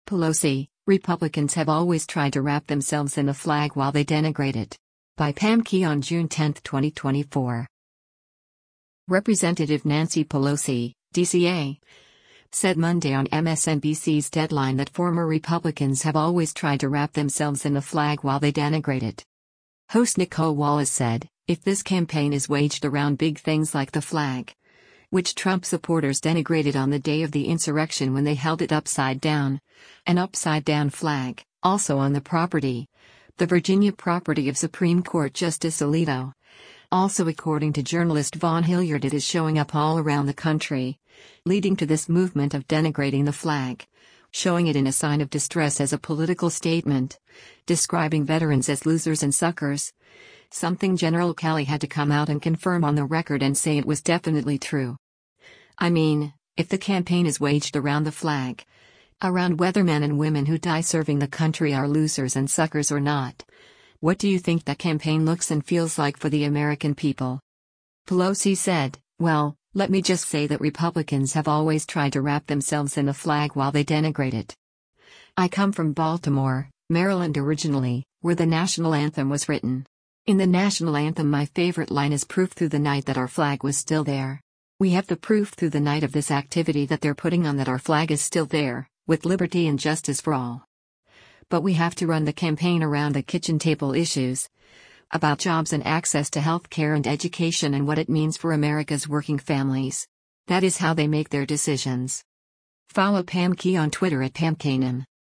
Representative Nancy Pelosi (D-CA) said Monday on MSNBC’s “Deadline” that former “Republicans have always tried to wrap themselves in the flag while they denigrate it.”